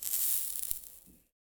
fake_fire_extinguish.1.ogg